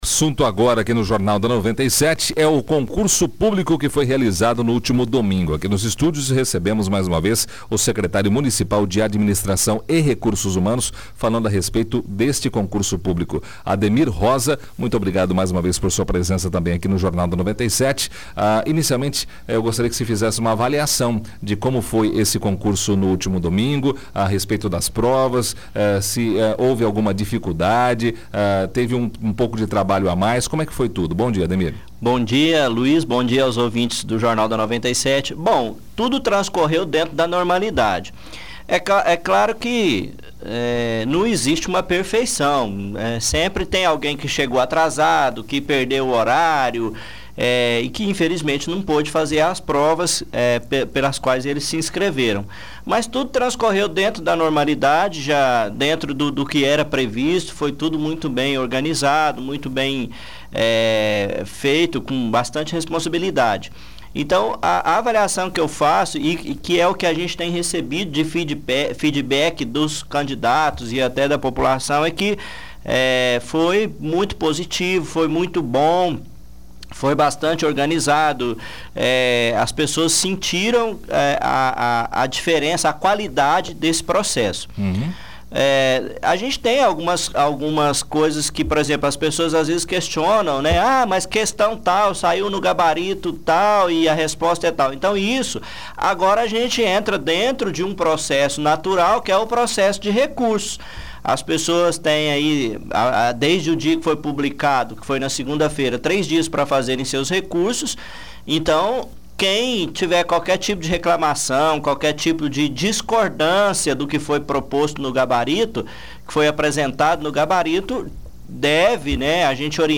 O Secretário de Administração e Recursos Humanos, Ademir Rosa, esteve na manhã de hoje (16/03), na segunda edição do Jornal da 97, falando a respeito do concurso público realizado no último domingo, ouça a entrevista.